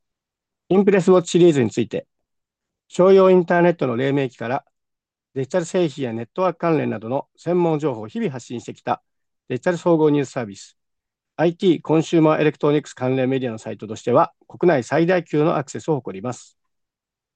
マイクの性能も本体録音とビデオ会議「Zoom」での録音を試しましたが、本体録音だと音質の差は感じられるものの、圧縮音源となるビデオ会議ではその差もさほど大きくありませんでした。